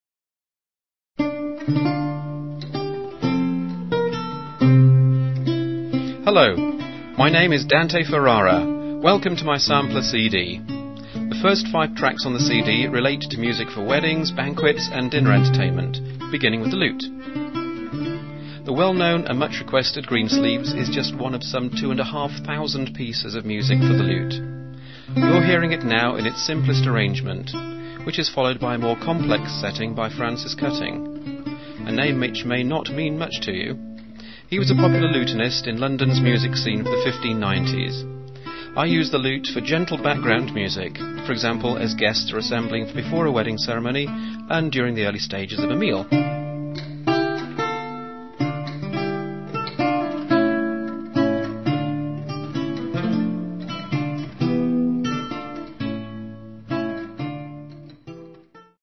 An Elizabethan gentleman specialising in the stringed instruments of the renaissance and early baroque periods.